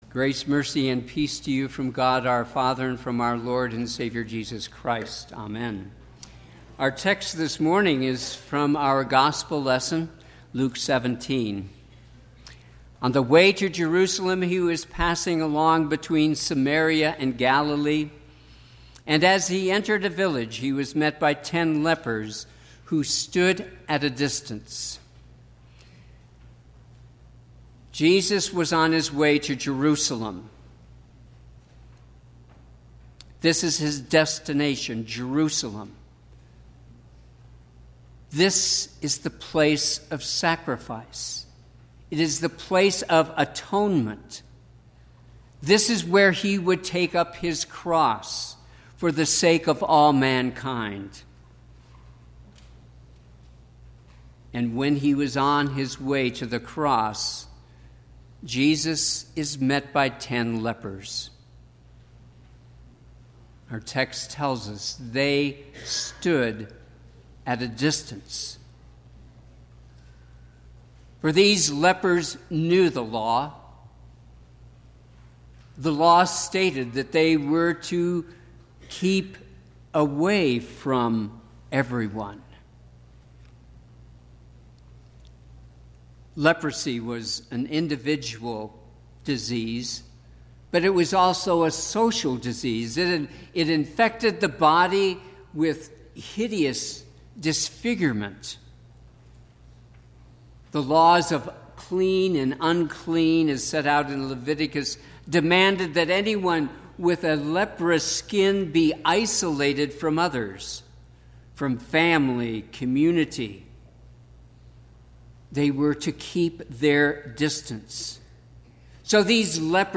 Fourteenth Sunday after Trinity
Sermon – 9/2/2018
Sermon_Sep2_2018.mp3